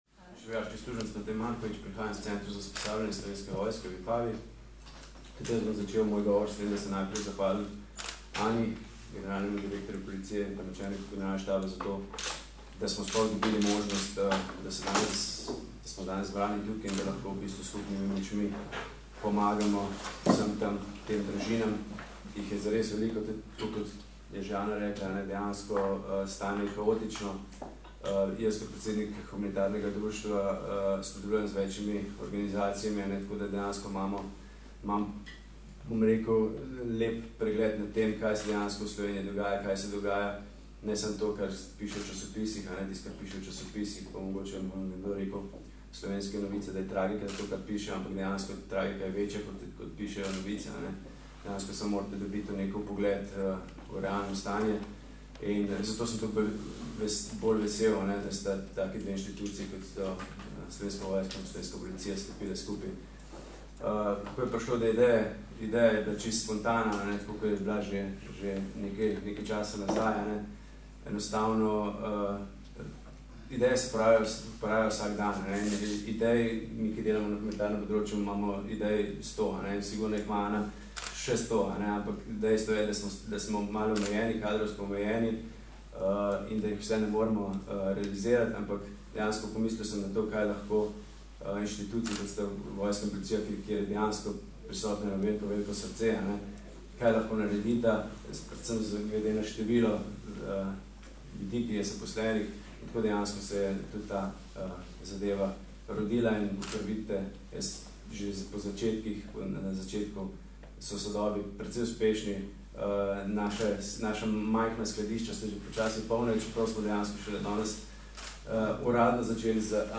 Sodelovanje smo predstavili na današnji novinarski konferenci.